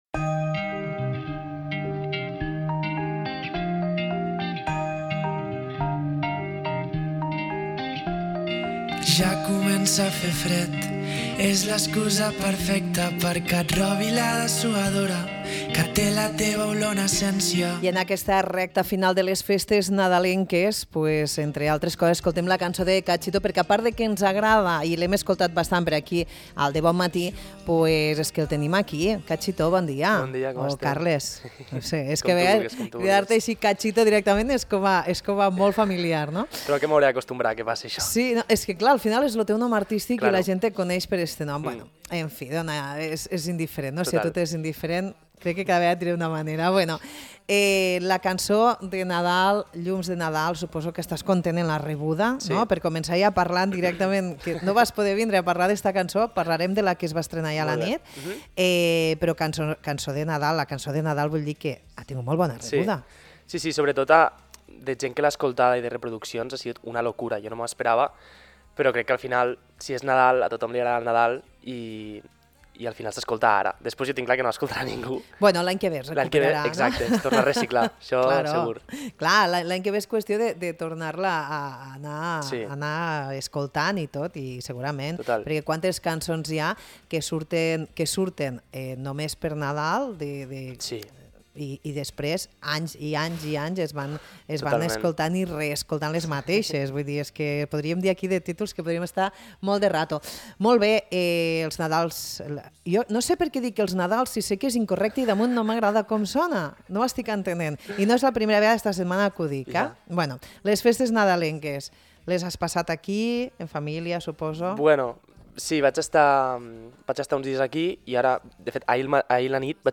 El músic ampostí ens parla també sobre futurs projectes i ens regala la versió acústica del nou senzill als estudis d’Imagina Ràdio.